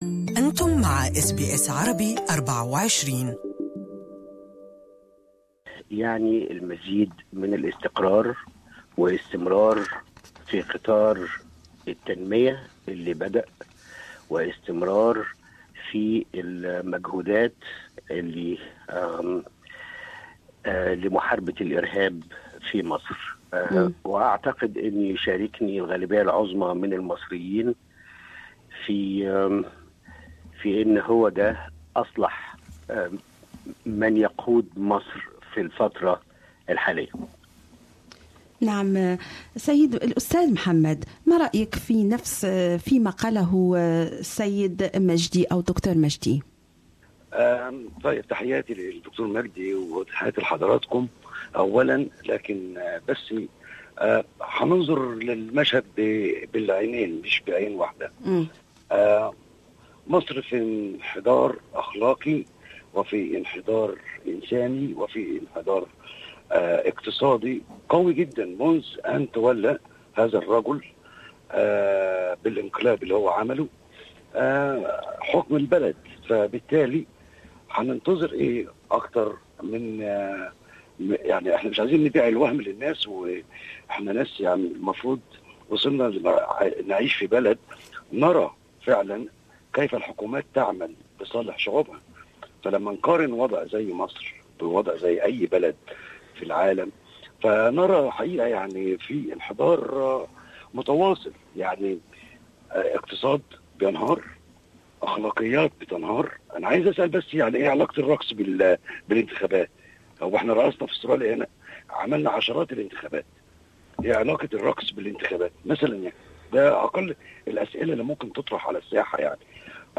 Debate on Egypt's elections